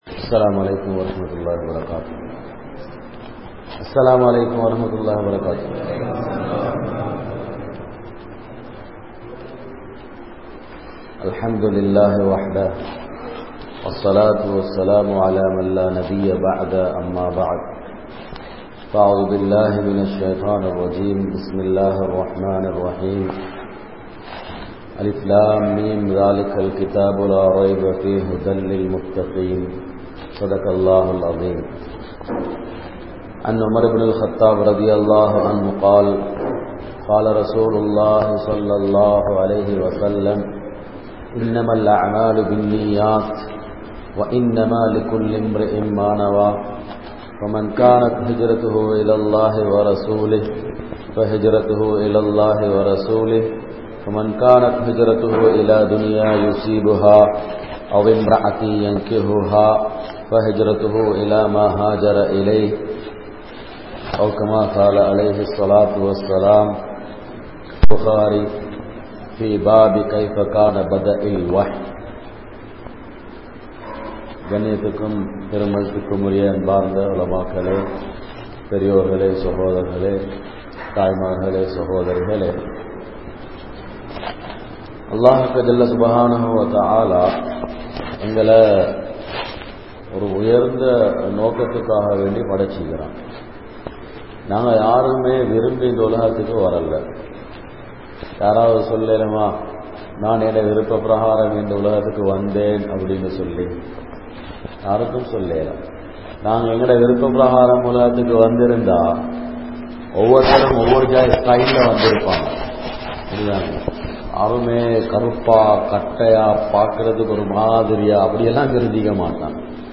Manaiviudan Naraha Vaalkai Vaalum Manitharhal(மனைவியுடன் நரக வாழ்க்கை வாழும் மனிதர்கள்) | Audio Bayans | All Ceylon Muslim Youth Community | Addalaichenai